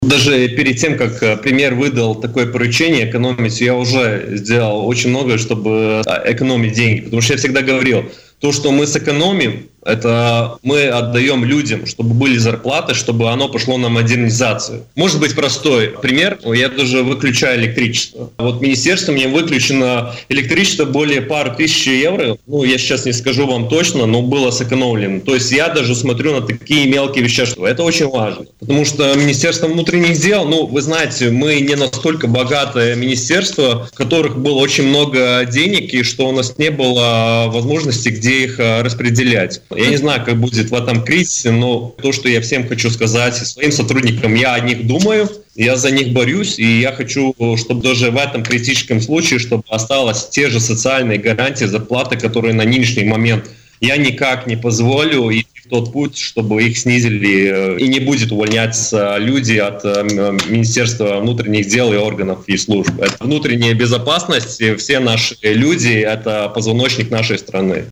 Об этом в интервью радио Baltkom рассказал министр внутренних дел Сандис Гиргенс.